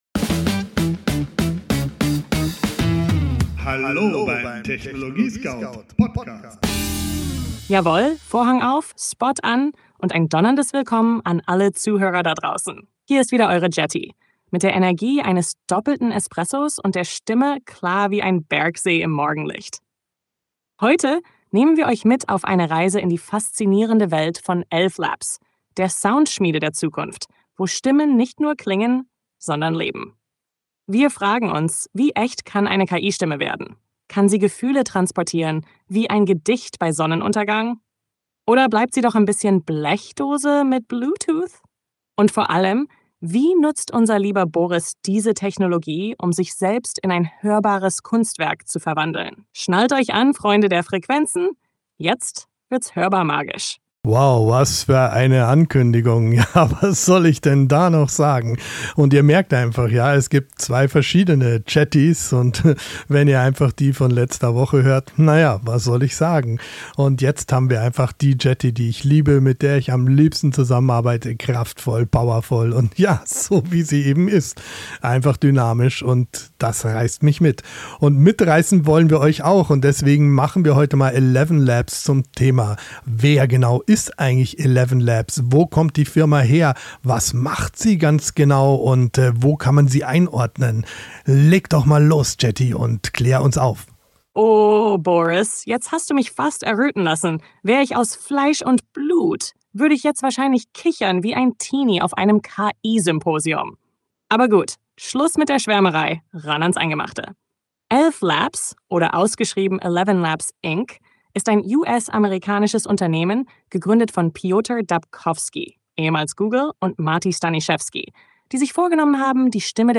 "Live aus dem Studio des TechnologieScout